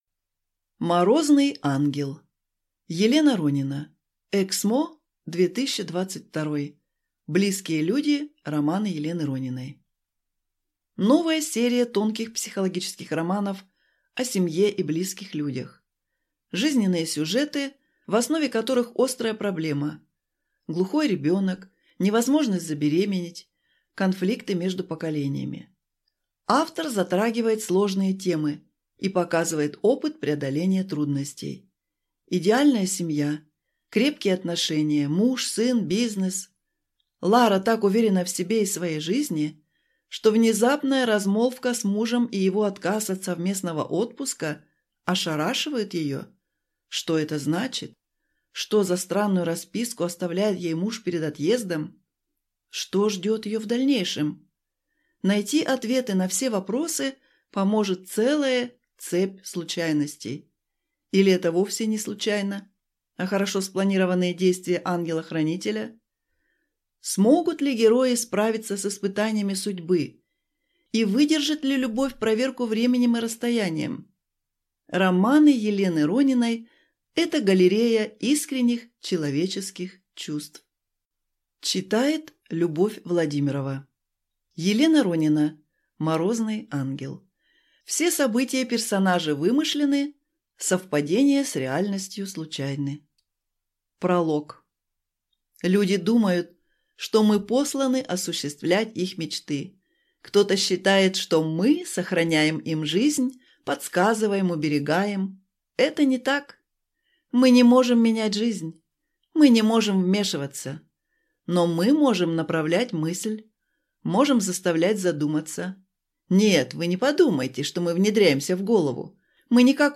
Аудиокнига Морозный ангел | Библиотека аудиокниг
Прослушать и бесплатно скачать фрагмент аудиокниги